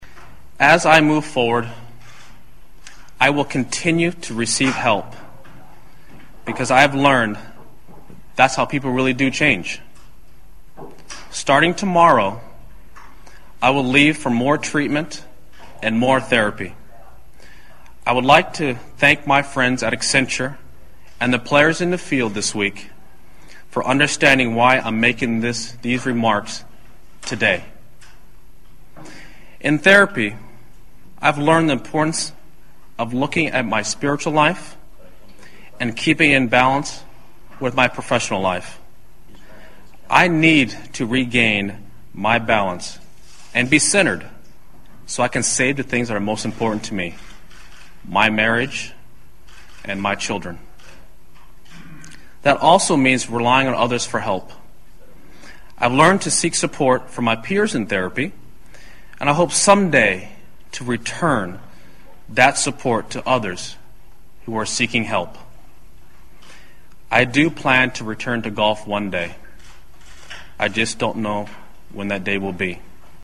偶像励志英语演讲 36:再相信我一次(7) 听力文件下载—在线英语听力室
在线英语听力室偶像励志英语演讲 36:再相信我一次(7)的听力文件下载,《偶像励志演讲》收录了娱乐圈明星们的励志演讲。